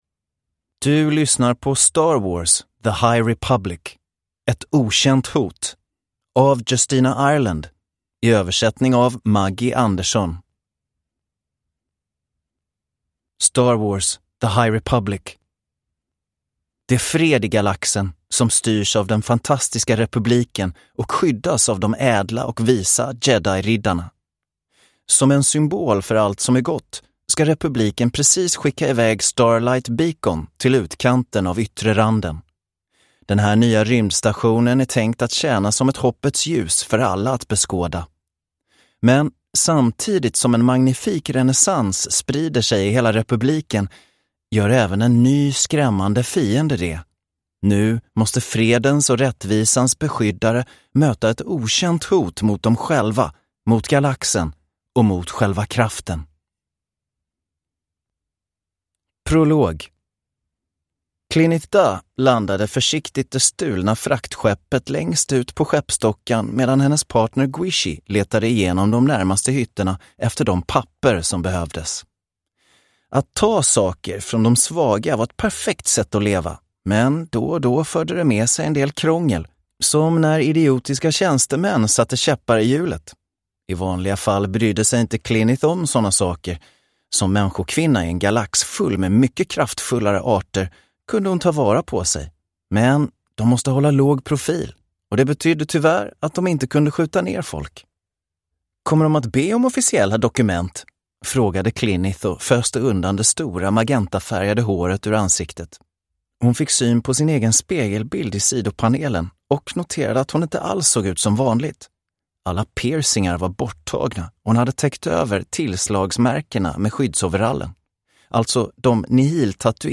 The High Republic. 1, Ett okänt hot – Ljudbok